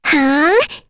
Kirze-Vox_Attack3.wav